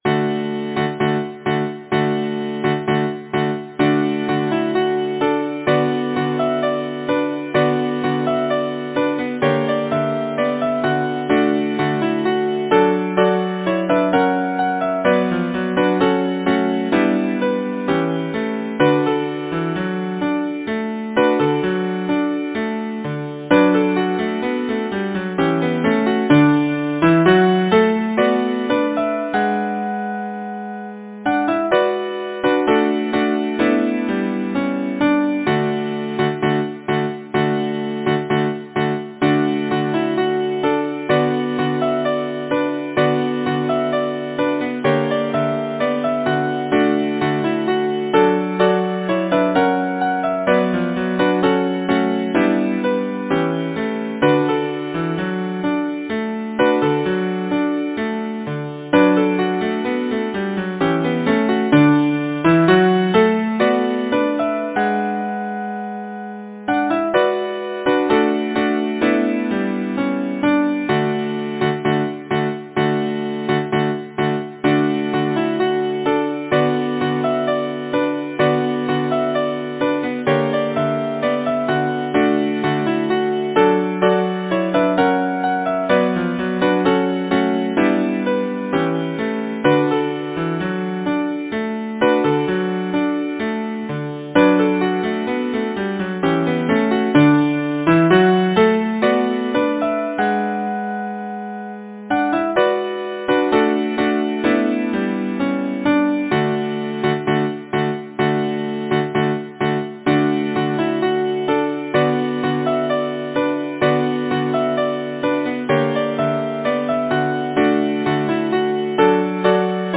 Title: The birks of Aberfeldy Composer: Anonymous (Traditional) Arranger: Harvey Worthington Loomis Lyricist: Robert Burns Number of voices: 4vv Voicing: SATB Genre: Secular, Partsong, Folksong
Language: Lowland Scots Instruments: A cappella
First published: 1910 American Book Company Description: Scottish folksong